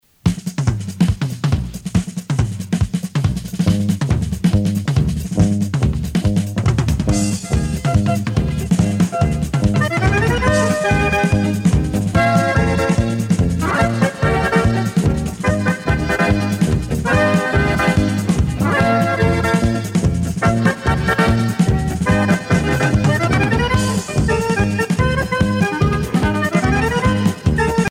danse : samba
Tempo Grande vitesse
Pièce musicale éditée